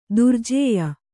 ♪ durjēya